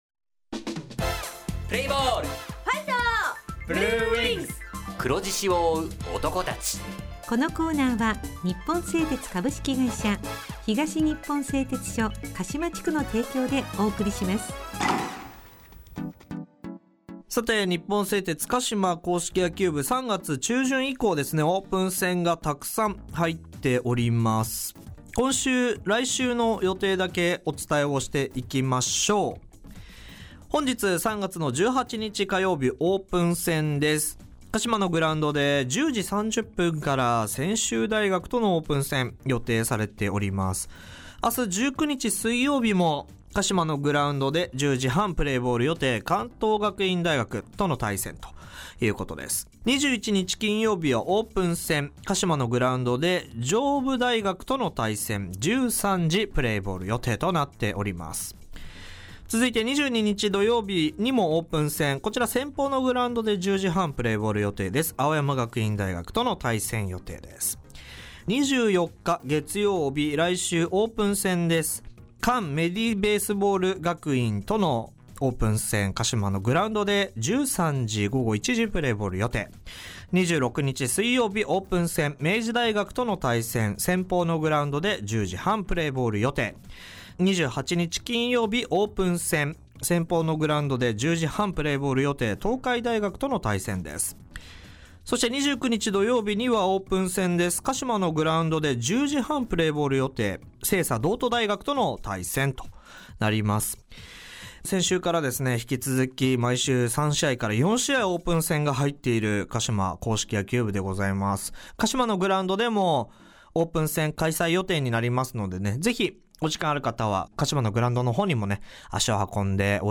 インタビュー
地元ＦＭ放送局「エフエムかしま」にて鹿島硬式野球部の番組放送しています。